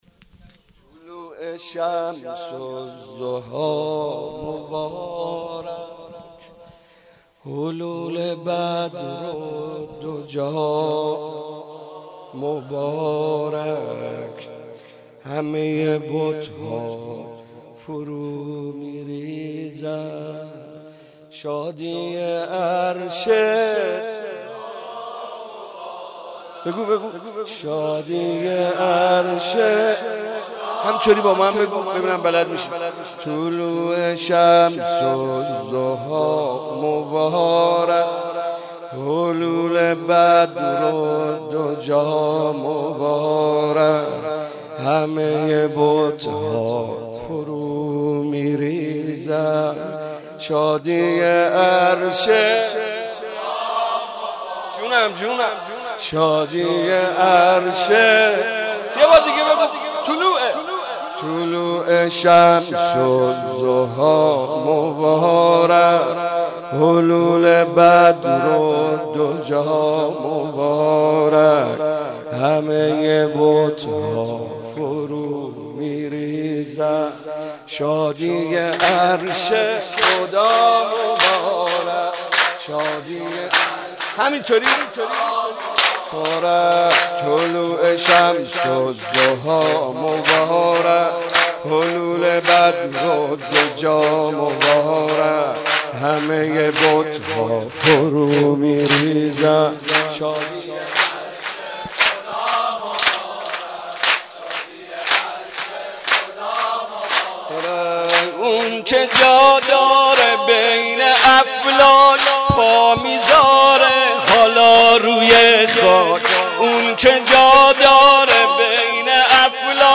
مداحی میلاد پیامبر (ص) و امام صادق(ع)